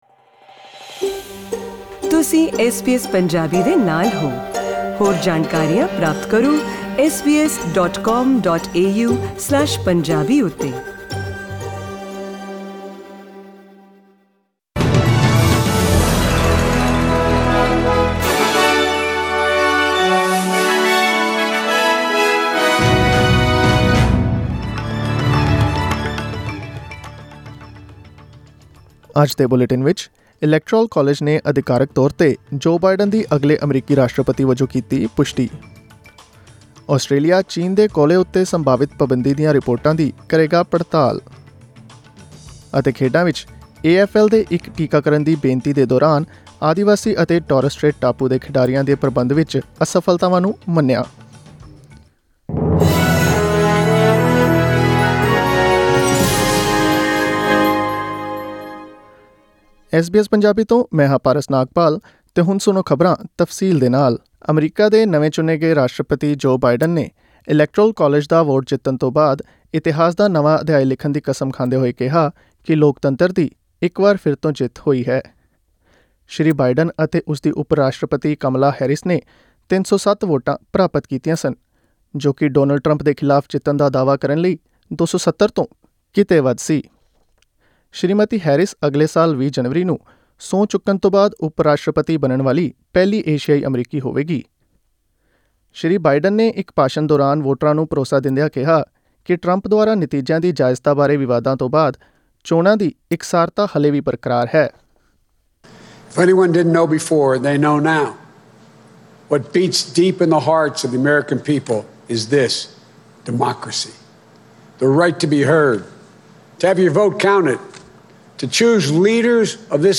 SBS Punjabi News 15 Dec: Electoral college confirms Joe Biden as next US President